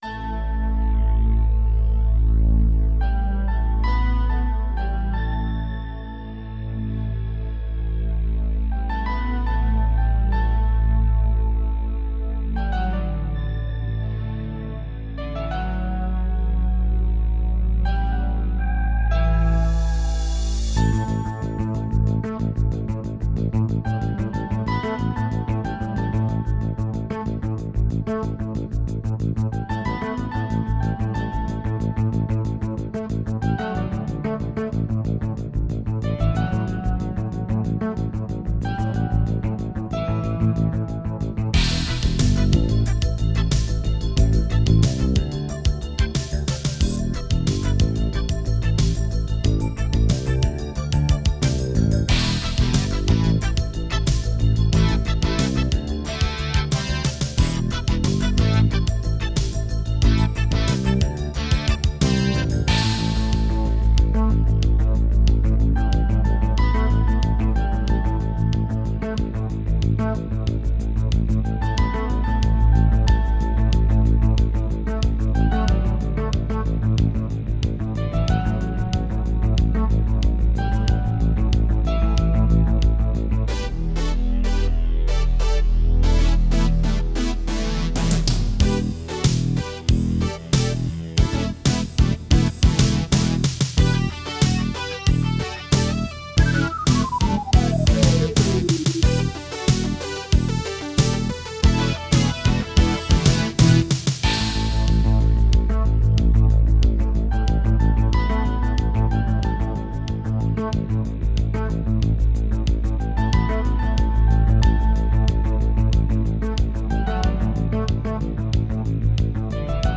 • On-Board Demos